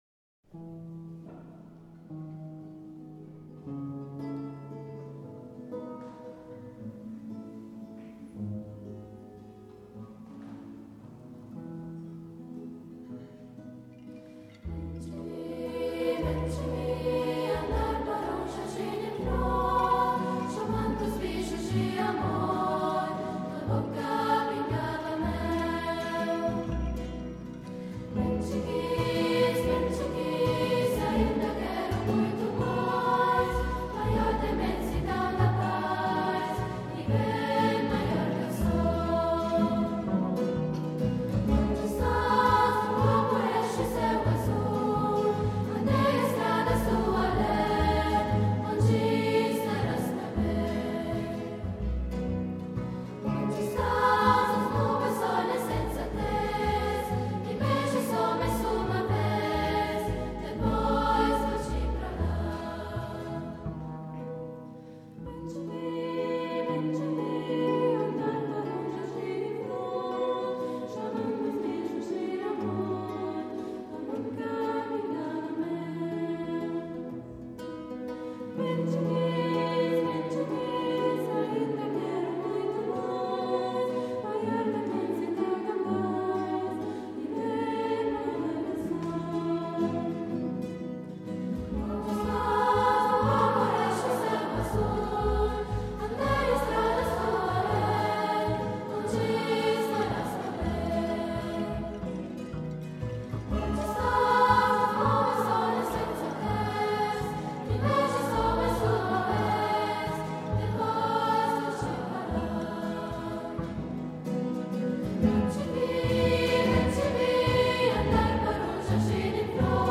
CD, Live-Aufnahme